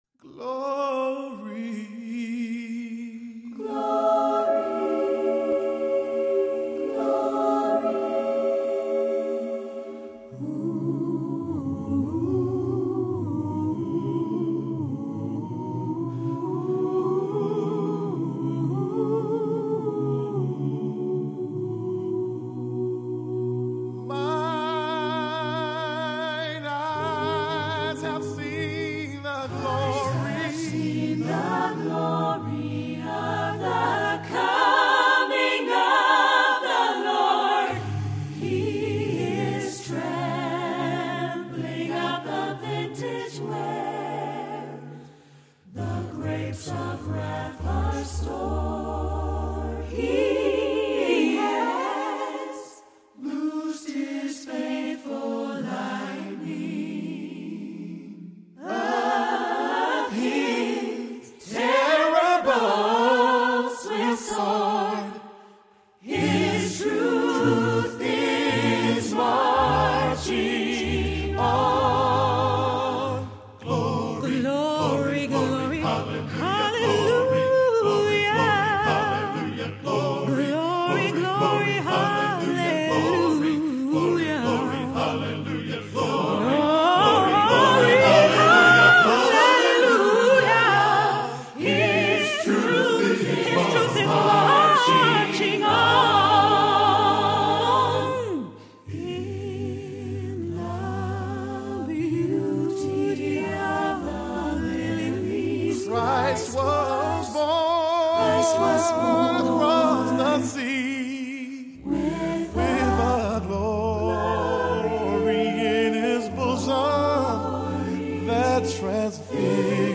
With that in mind, this, a cappella arrangement, begins and ends with the simple haunting word glory .
In between these mournful bookends, you might hear flag-waving, hope, despair, victory or defeat, for it is certainly all there. For myself, however, this entire piece is about loss.